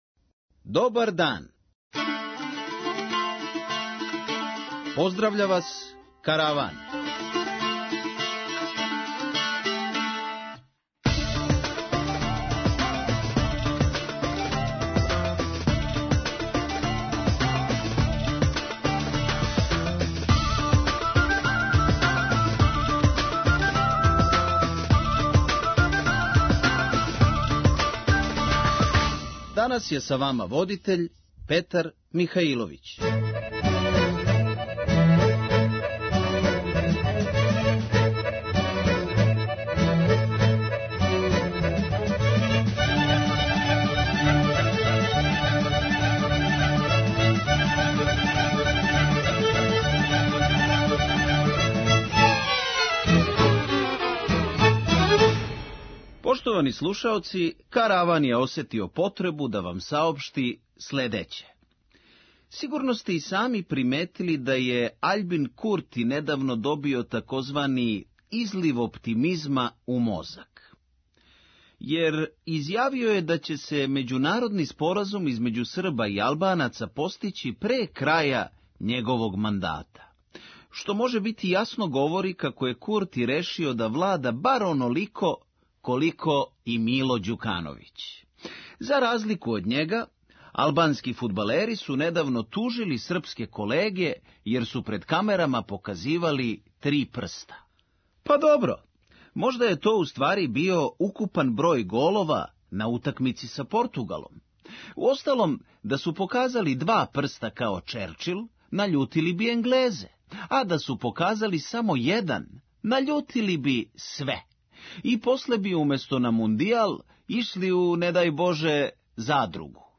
Хумористичка емисија